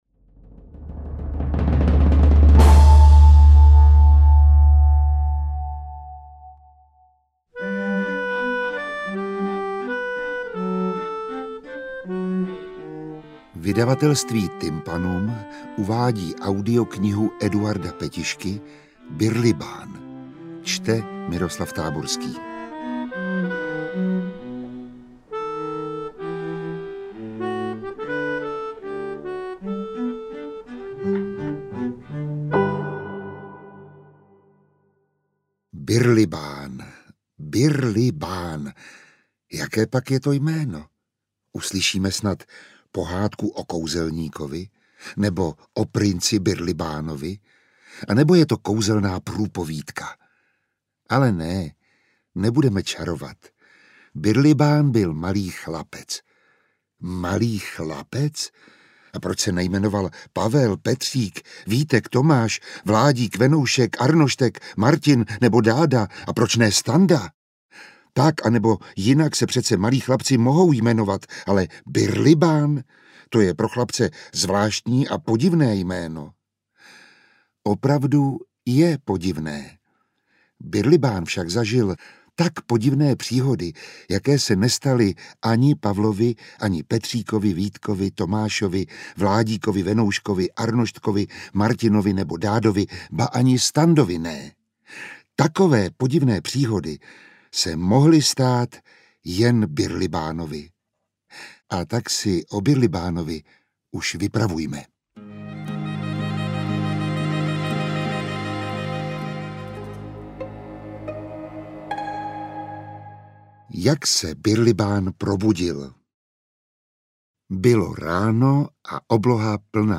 Interpret:  Miroslav Táborský